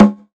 MURDA_PERCUSSION_KNOCK.wav